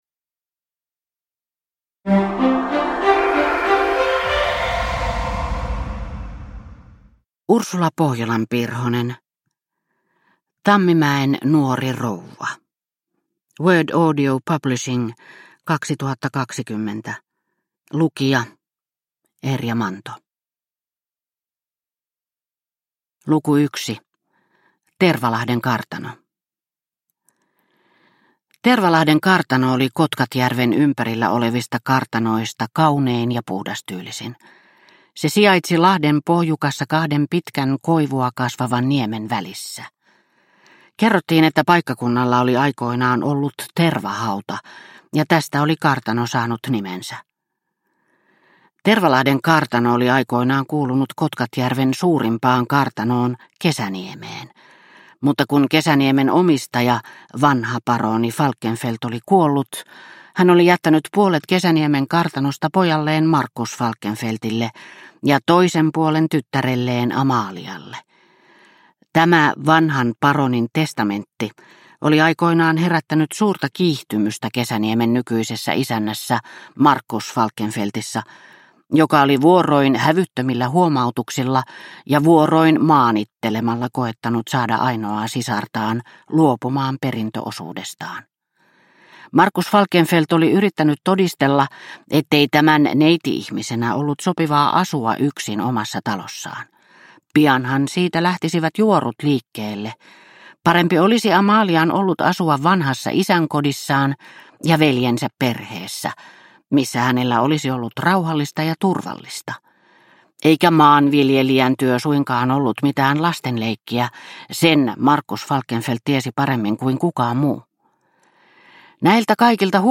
Tammimäen nuori rouva (ljudbok) av Ursula Pohjolan-Pirhonen